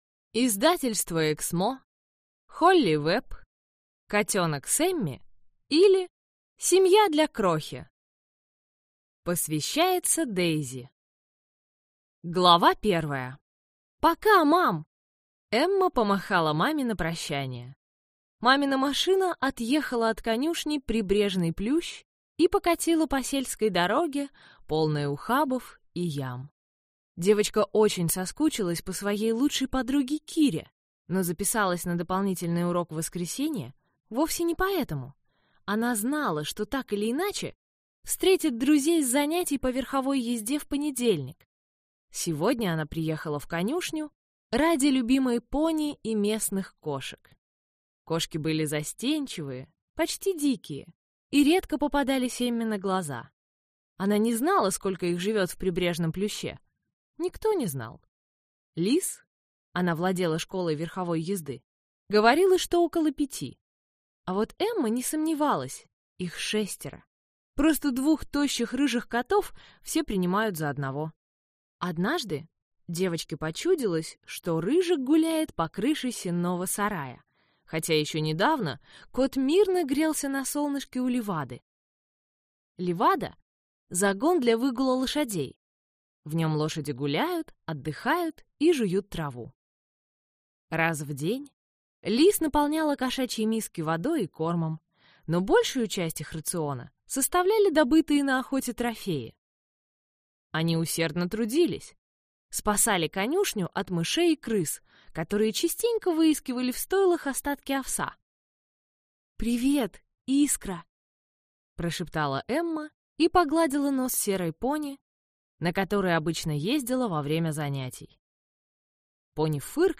Аудиокнига Котенок Сэмми, или Семья для крохи | Библиотека аудиокниг